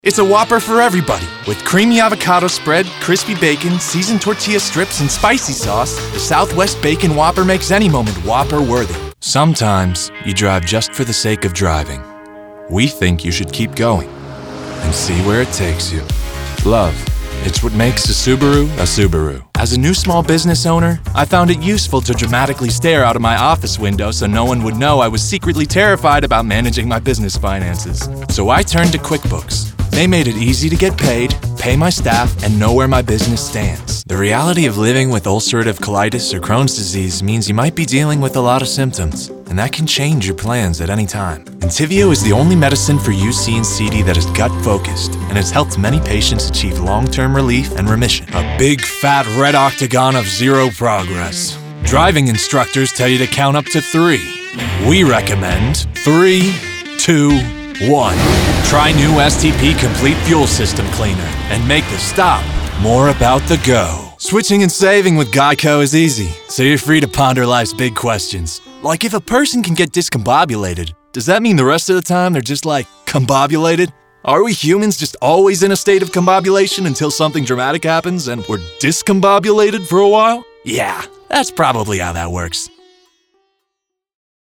Demo
COMMERCIAL 💸
cool
epic
husky